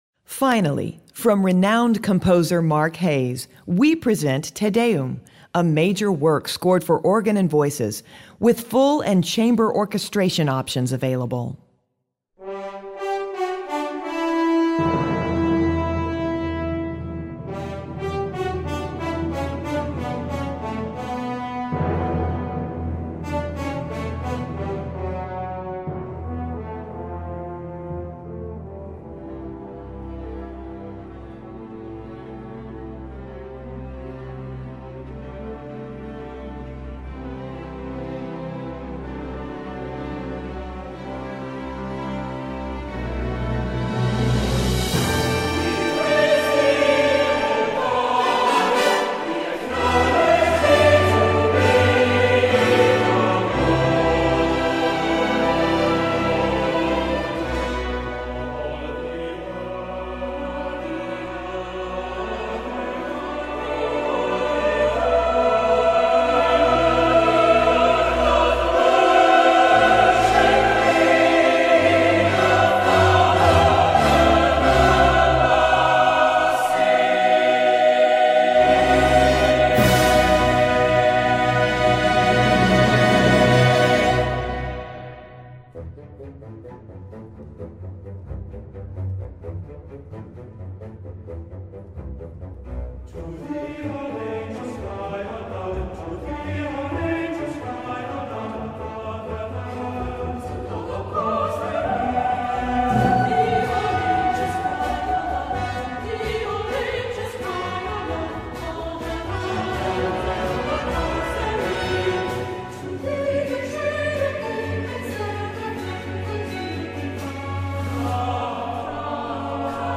Choral Music → Major Choral Works